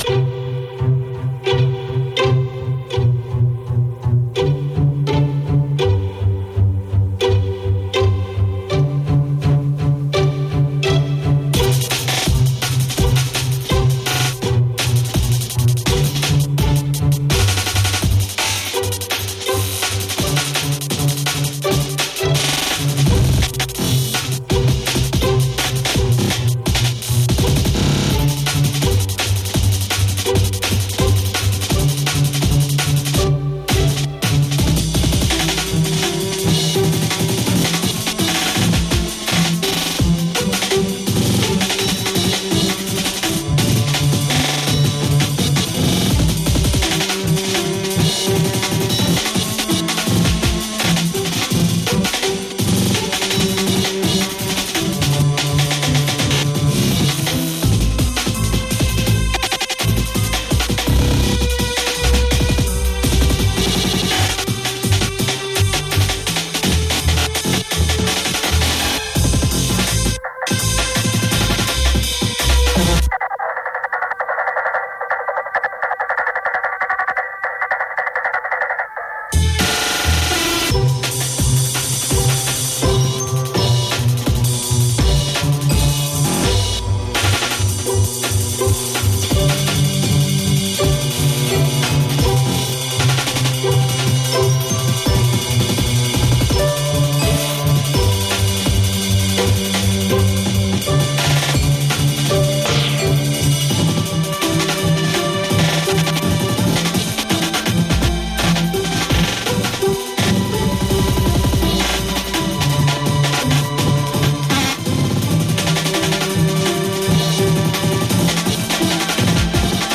venue Flemington Racecourse event Big Day Out